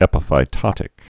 (ĕpə-fī-tŏtĭk)